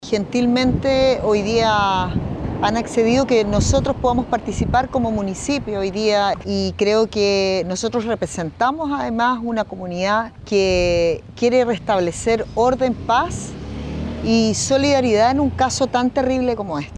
Por su parte, la alcaldesa de Quilpué, Carolina Corti, enfatizó que ofrecieron a acompañar a la familia para visibilizar el caso y su importancia, mientras las investigaciones avanzan.
cu-perro-jack-alcaldesa.mp3